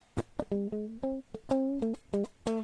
GUITAR LOOPS - PAGE 1 2 3 4